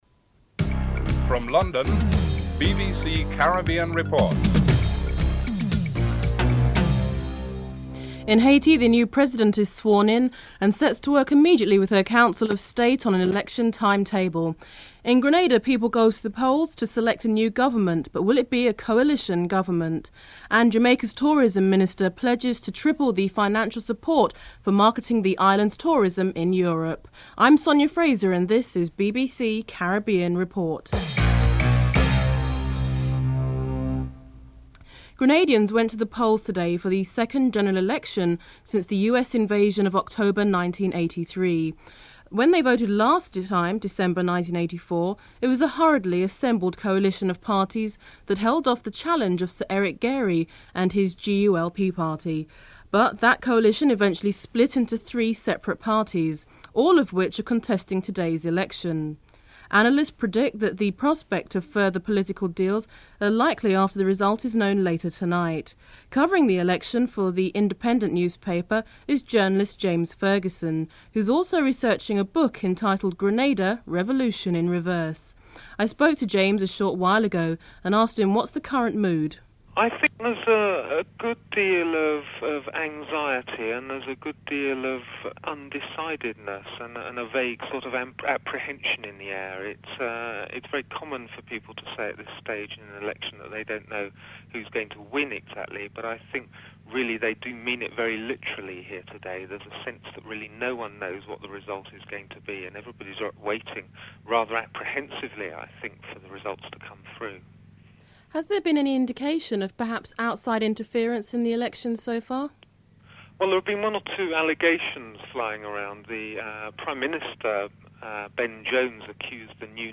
Includes a musical interlude at the beginning of the report.
Headlines (00:00-01:27)